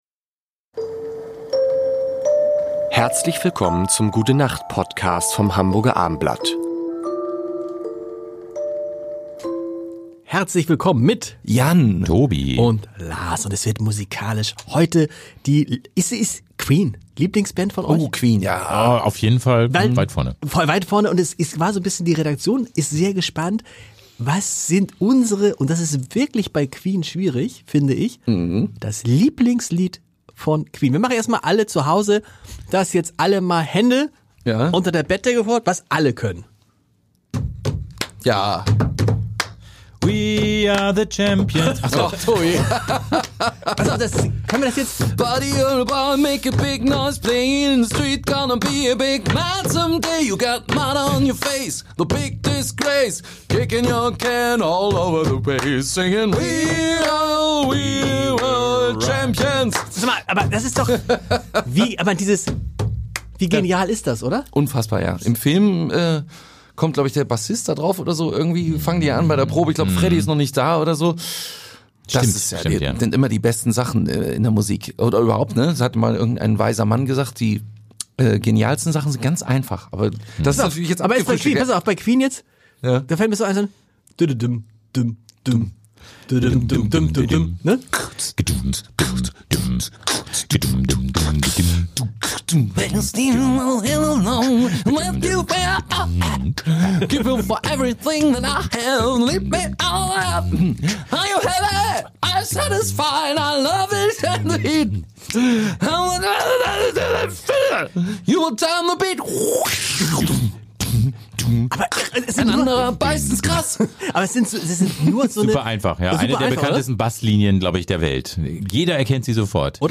Die schönsten Lieder einer der größten Bands – live.